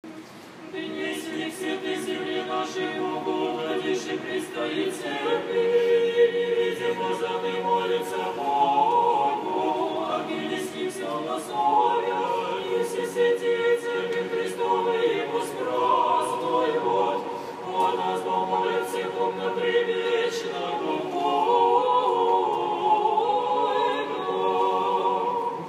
Кондак, глас 3
Кондак.mp3